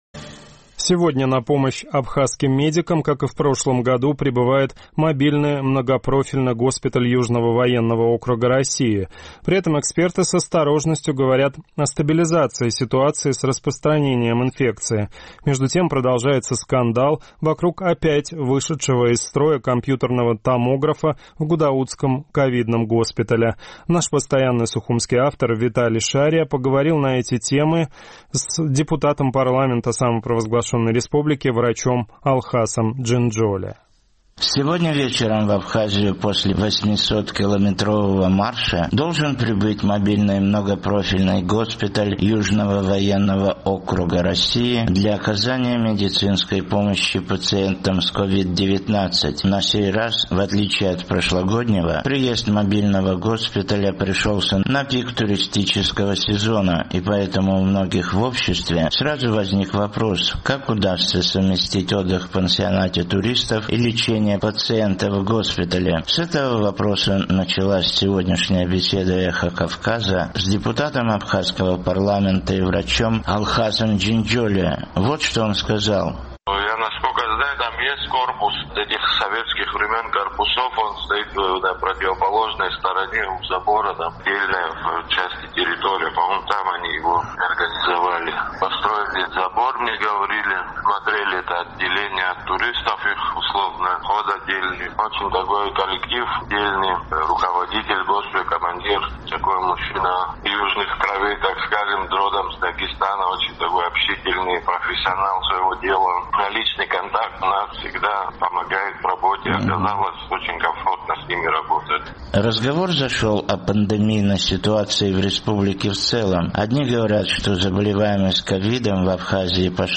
С этого вопроса началась сегодняшняя беседа «Эха Кавказа» с депутатом абхазского парламента и врачом, членом координационного штаба республики по защите от коронавирусной инфекции Алхасом Джинджолия.